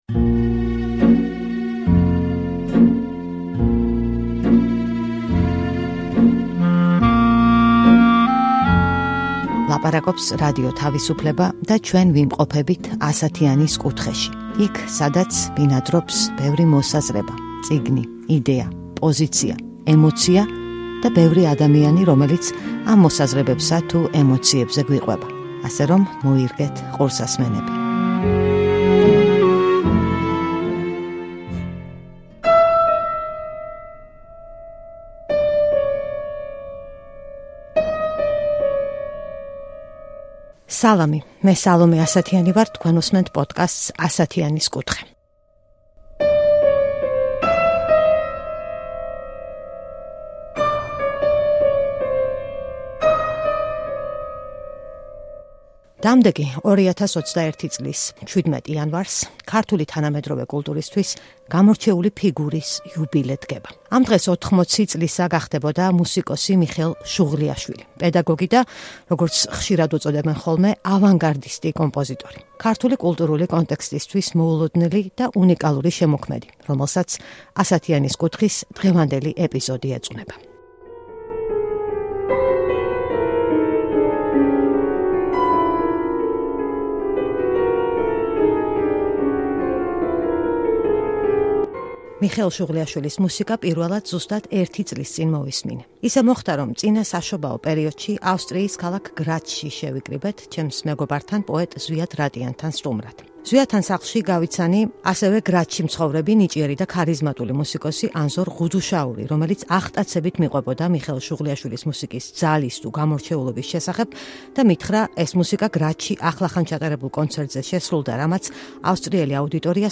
„ასათიანის კუთხის“ ეს ეპიზოდი ეძღვნება მუსიკოს მიხეილ შუღლიაშვილს - პედაგოგს და ავანგარდისტ კომპოზიტორს. ეპიზოდის სტუმრებთან ერთად გავიხსენებთ მის შემოქმედებას და ცხოვრების გზას და მოვუსმენთ ნაწყვეტებს მისი გამორჩეული ძალის და ენერგიის მატარებელი მუსიკალური ნაწარმოებებიდან.